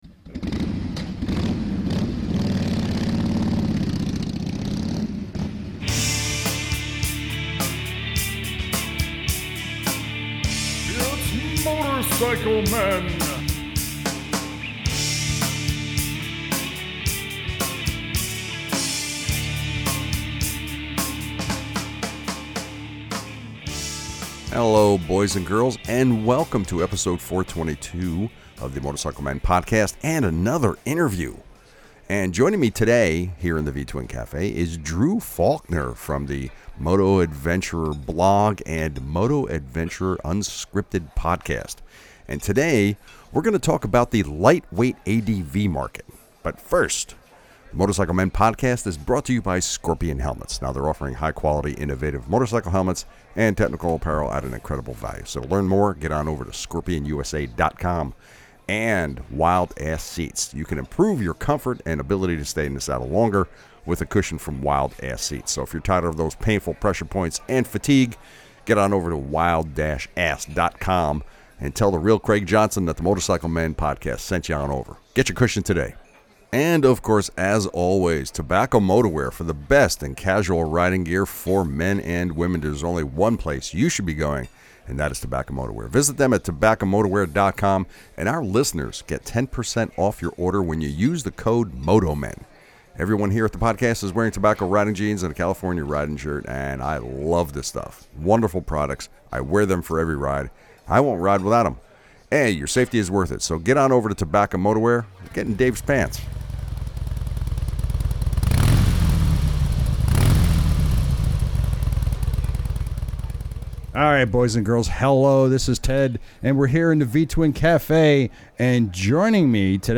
Episode 422 - Interview